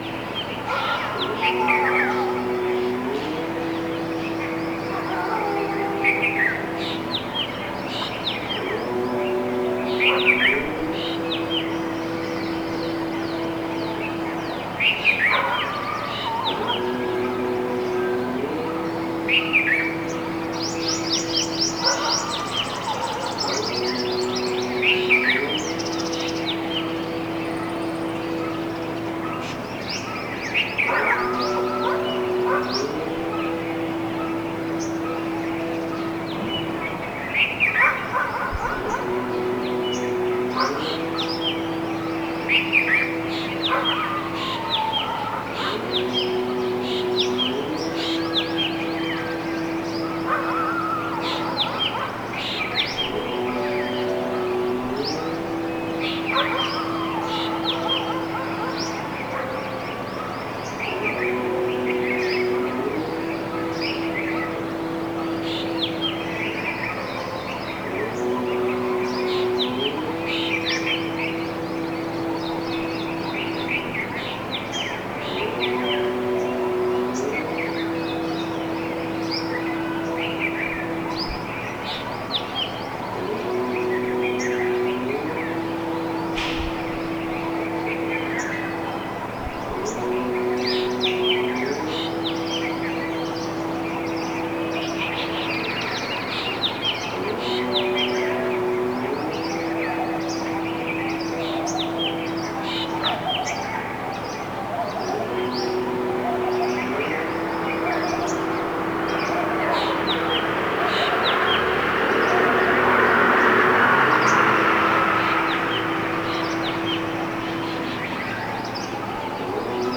oa-india-indore-paramanand-institute-of-yoga-sciences-and-research.mp3